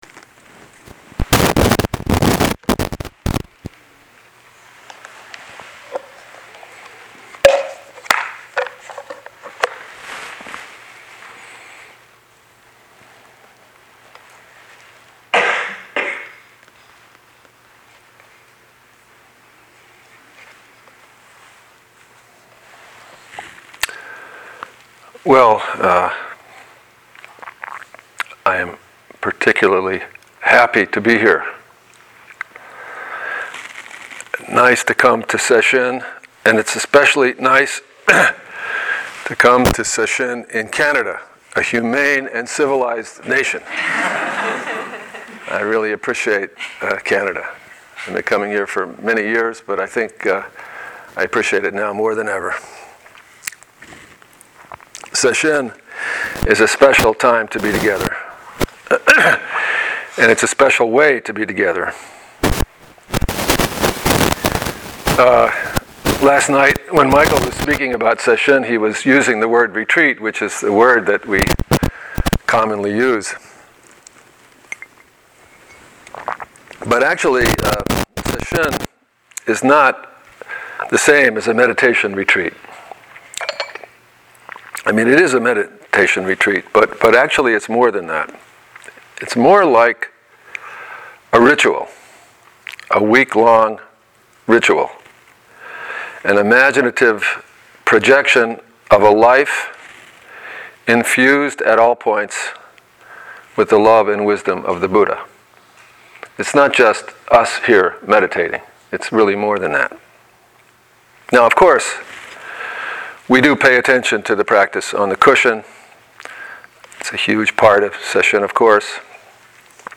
Loon Lake Sesshin Evening Talk 1
17th annual Loon Lake Sesshin dharma talks